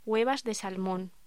Locución: Huevas de salmón